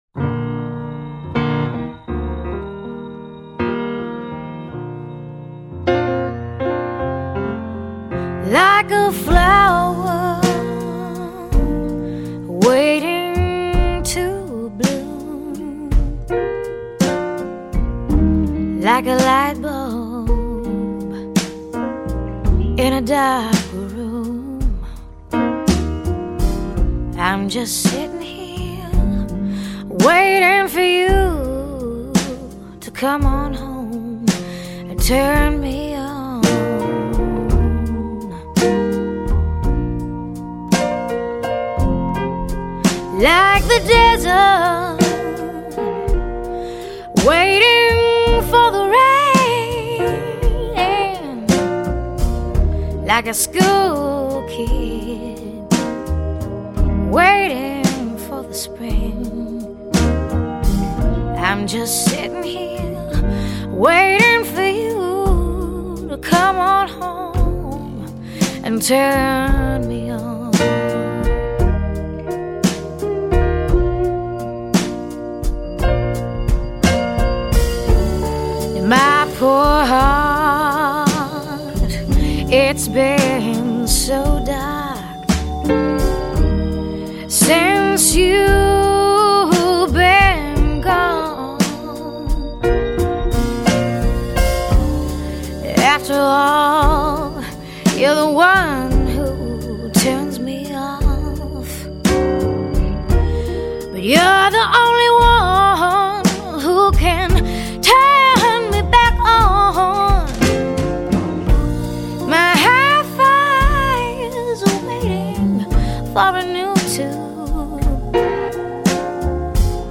音乐类型：爵士乐
慵慵懒懒的爵士轻乐,放松心思,一杯咖啡,作沙发聆听....